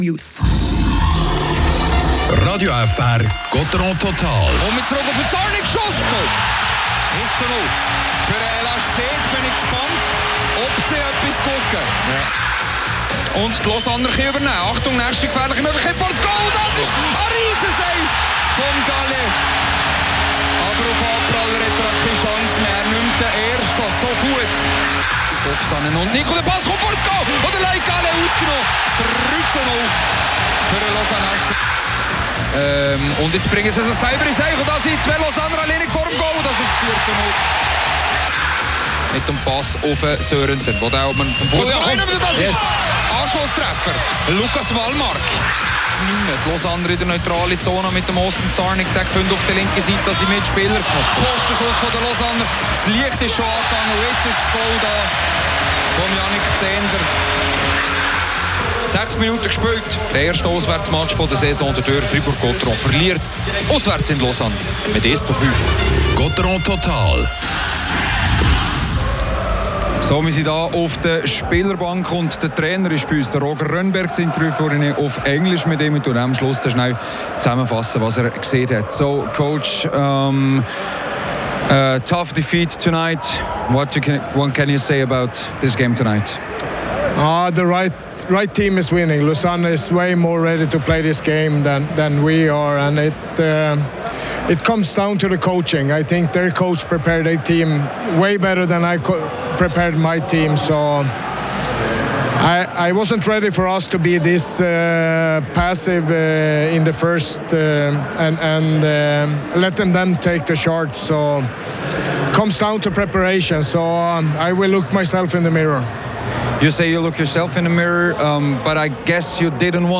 Spielanalyse